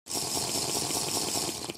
Minecraft Drinking Sound